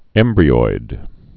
(ĕmbrē-oid)